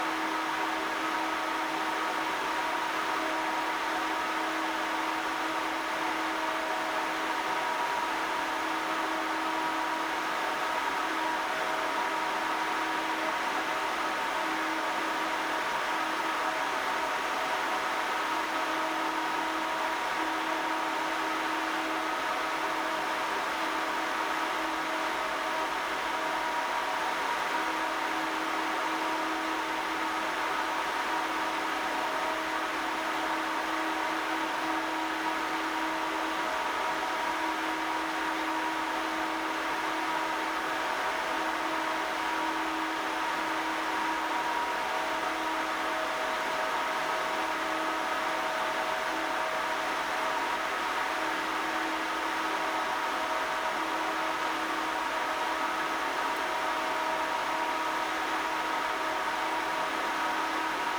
There is a noise spike in the 250-350Hz region.
I have recorded the signals shown above, but please keep in mind that I’ve enabled Automatic Gain Control (AGC) to do so to make it easier for you to reproduce them.
75% Fan Speed
XPG-Nidec-Vento-Pro-120-PWM-75-Fan-Speed.mp3